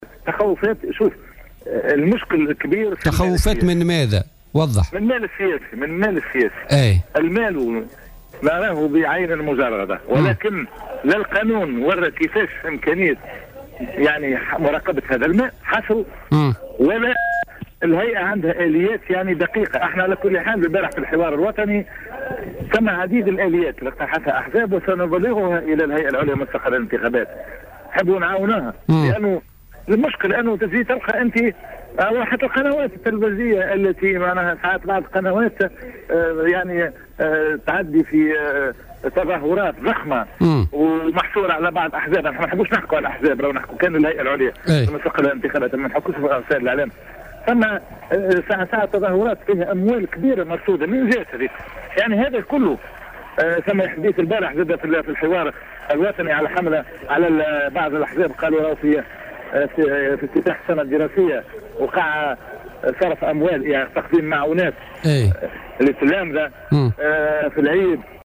قال رئيس الرابطة التونسية للدفاع عن حقوق الإنسان،عبد الستار بن موسى اليوم الثلاثاء في مداخلة له في برنامج "بوليتيكا" إن الهيئة العليا المستقلة للانتخابات لا تملك آليات دقيقة كفيلة بمراقبة المال السياسي.